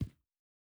Shoe Step Stone Medium C.wav